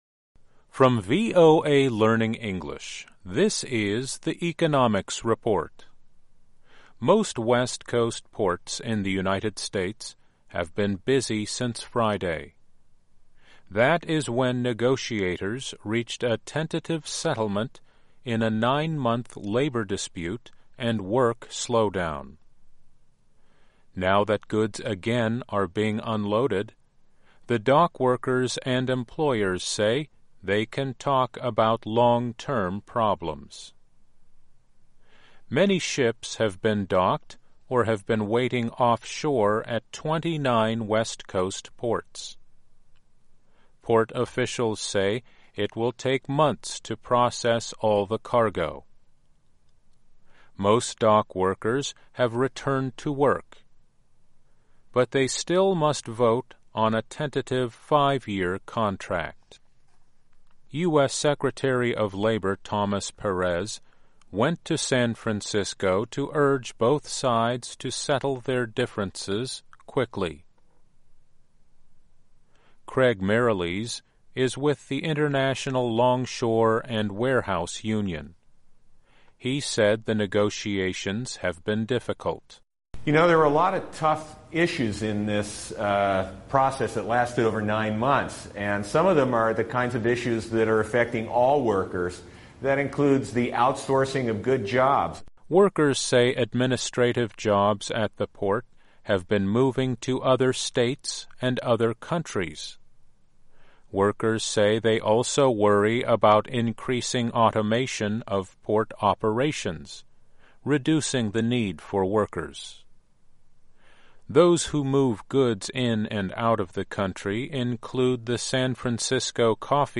Learning English as you read and listen to news and feature stories about business, finance and economics. Our daily stories are written at the intermediate and upper-beginner level and are read one-third slower than regular VOA English.